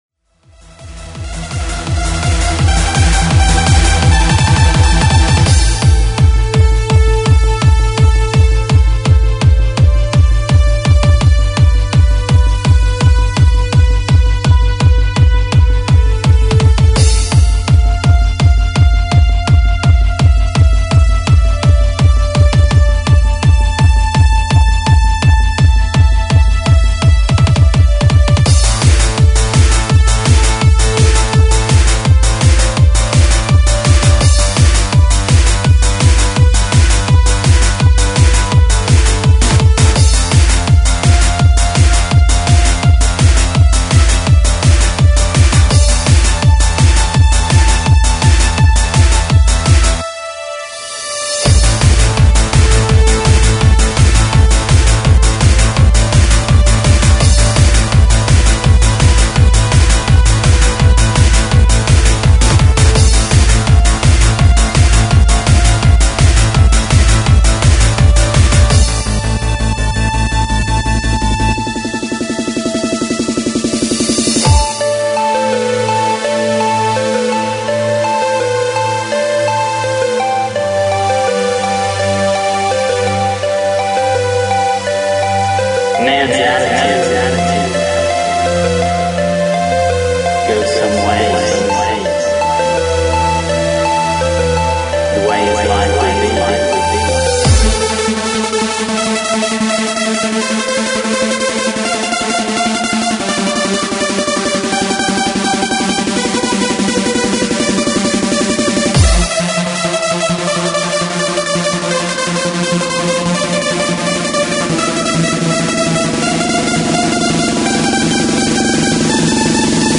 Freeform/Finrg/Hardcore